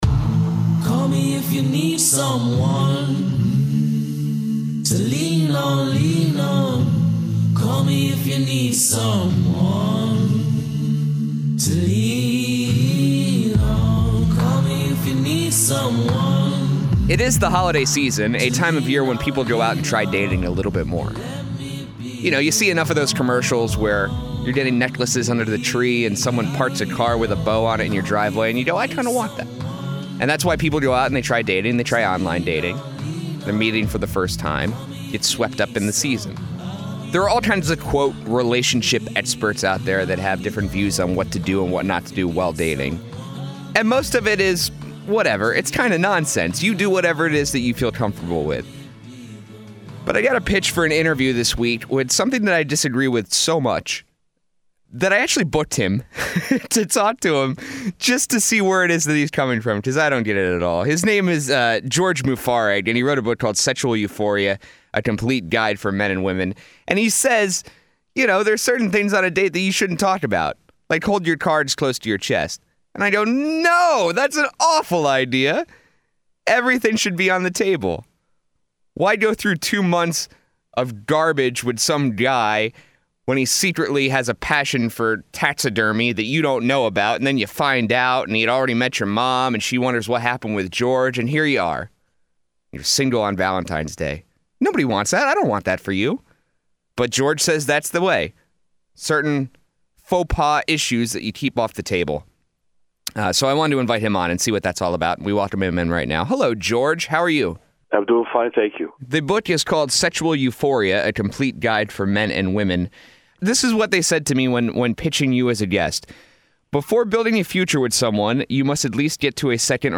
Interview: What Not To Say On A First Date